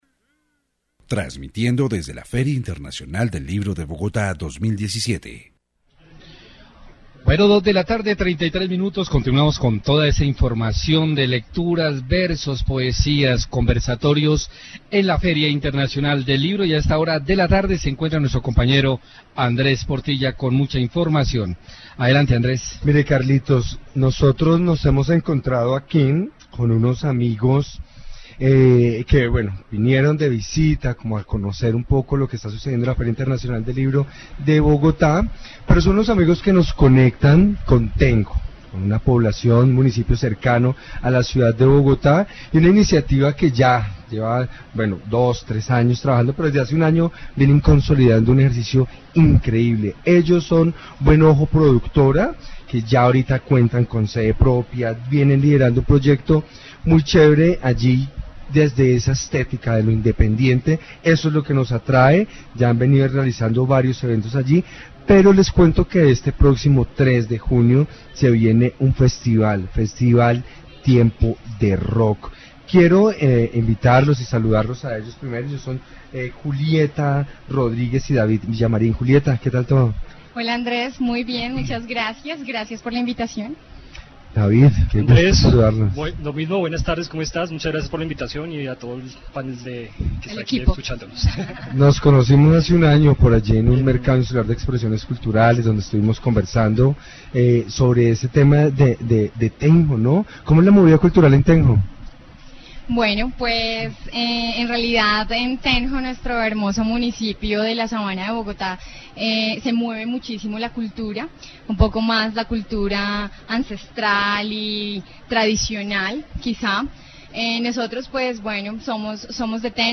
Feria del Libro 2017. Informe radial